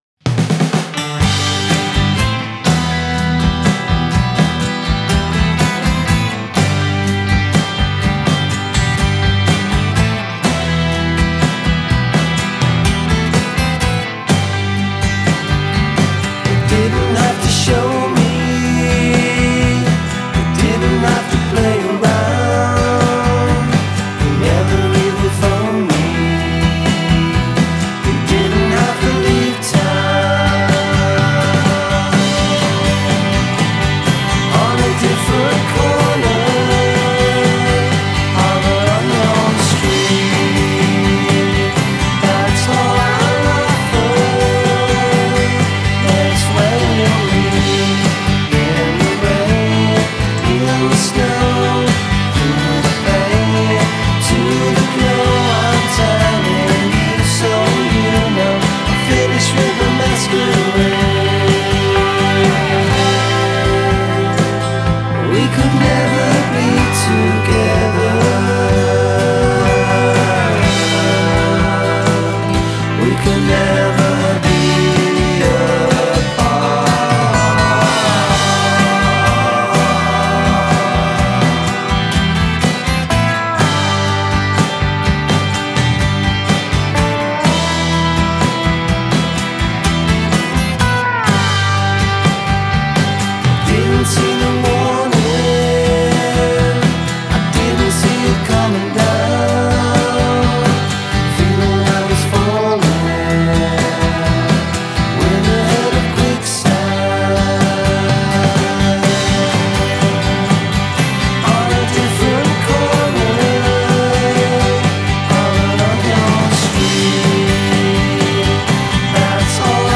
Byrdsian
with it’s killer jangle and harmony vocal-stoked hooks